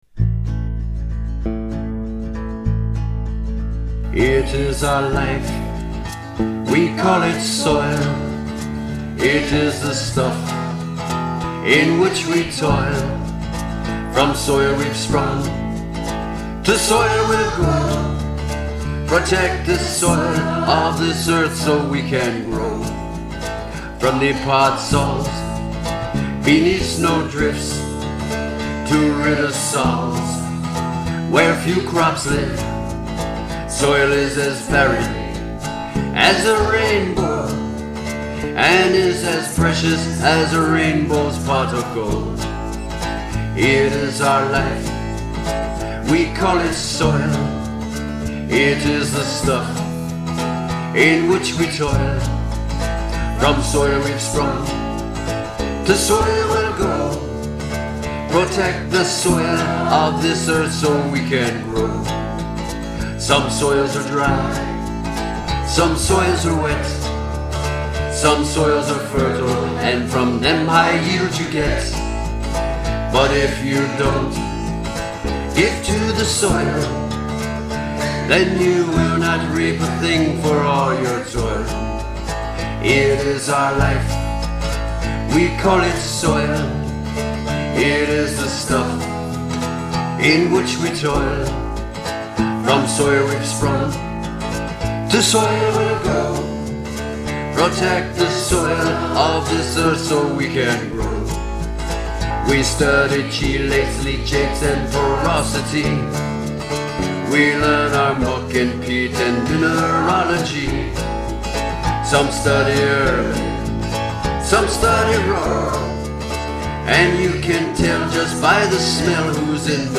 First sung at the closing ceremony of the 18th World Congress of Soil Science on 15th July 2006